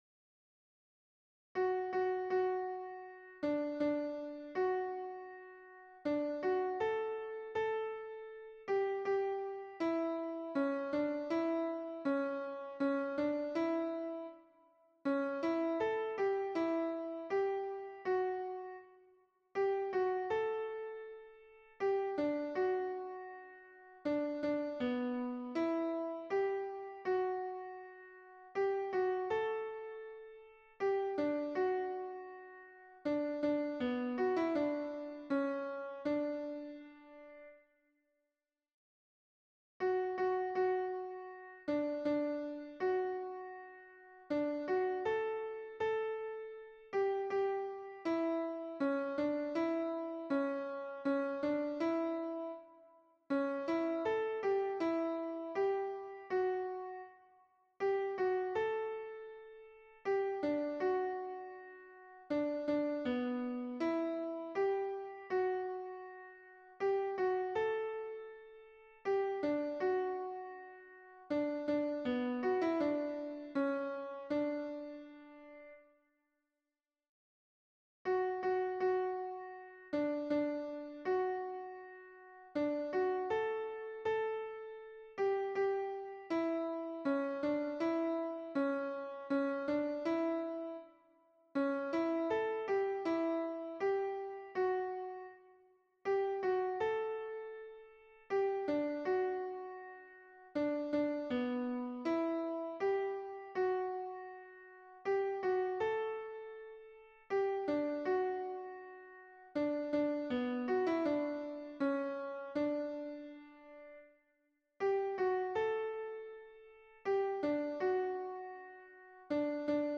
MP3 version piano
Alto